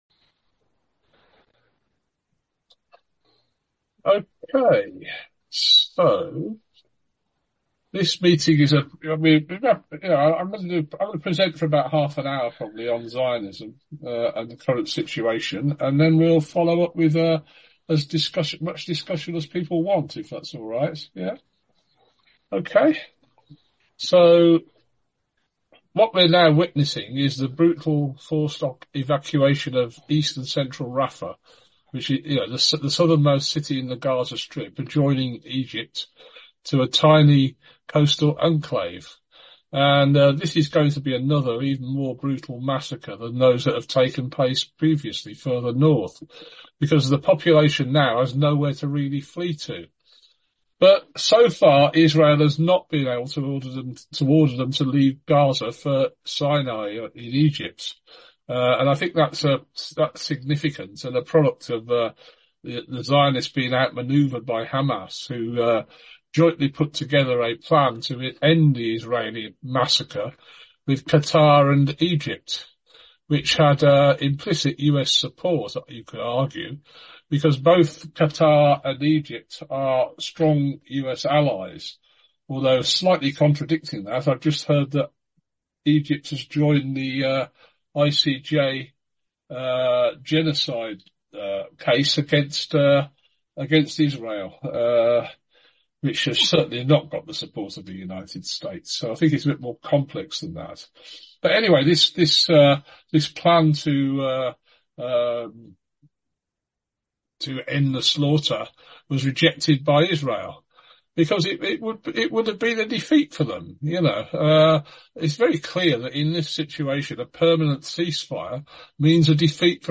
Public Forum on Marxism, Imperialism and Populism, 21/07/2024. An expanded view of current conflicts in the light of Joseph Seymour’s useful essay ‘On Bourgeois Class-Consciousness’ from 1977. The text of the presentation is here, and a recording of the presentation and discussion is here.